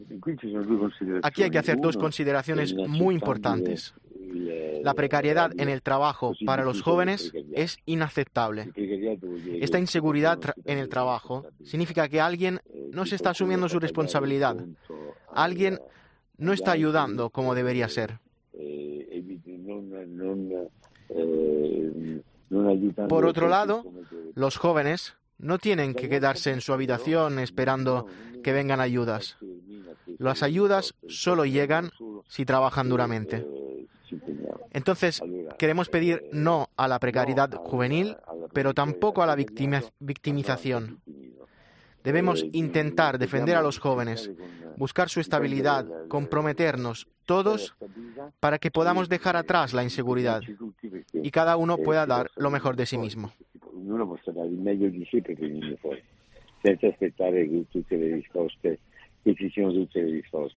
ECCLESIA tuvo la oportunidad de hablar el pasado mes de octubre con el arzobispo de Bolonia sobre varios temas antes del Encuentro Internacional organizado por Sant'Egidio en Roma